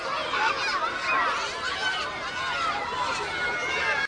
cry02.mp3